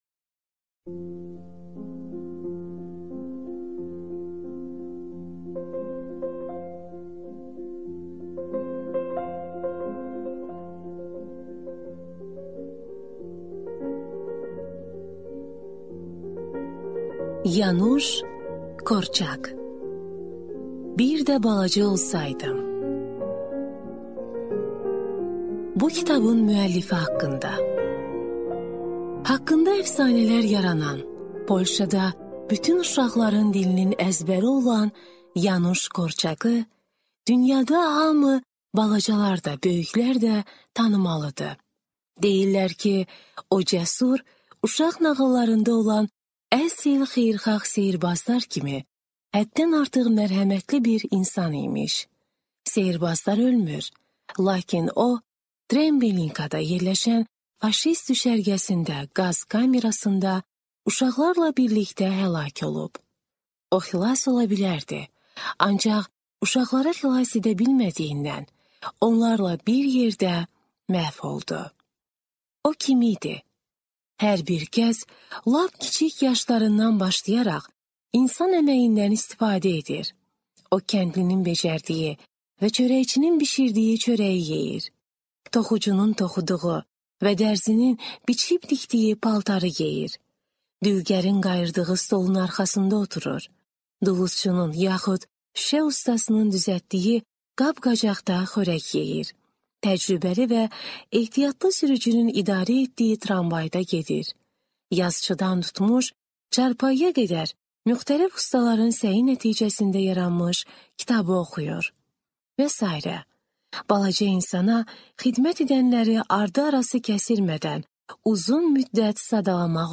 Аудиокнига Bir də balaca olsaydım | Библиотека аудиокниг